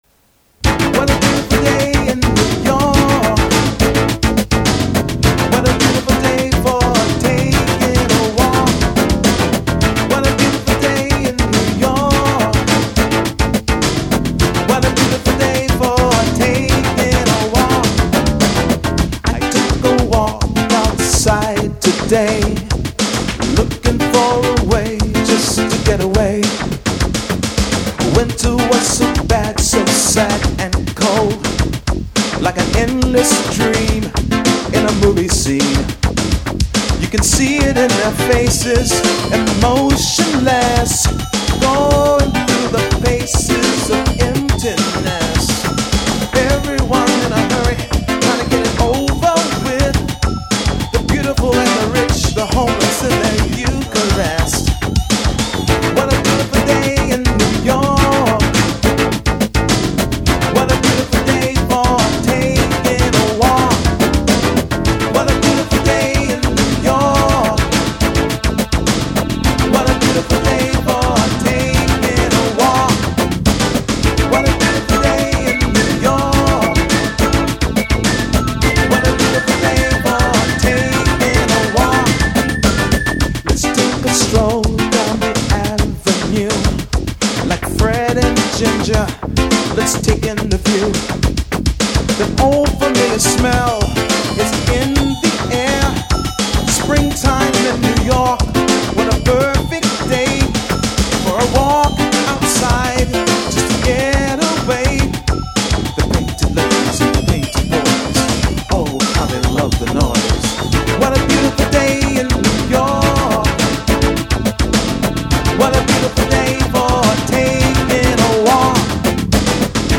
during an all night session